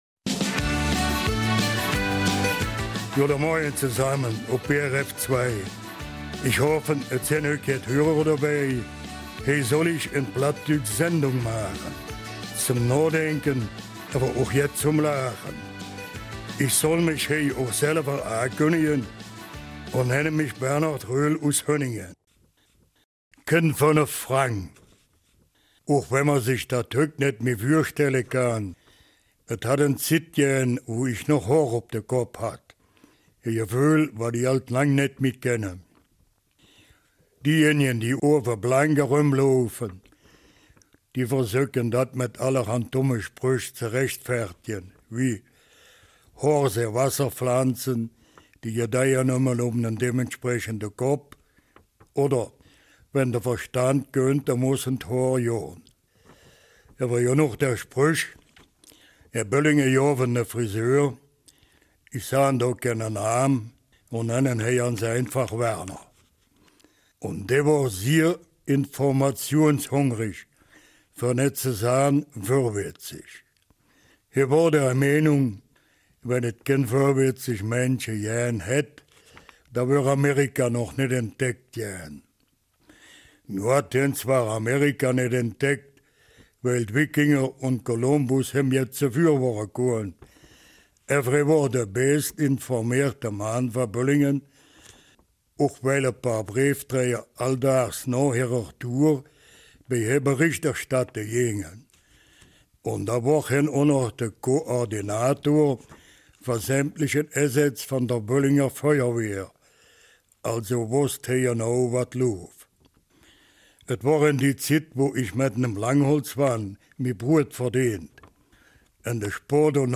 Eifeler Mundart - 27.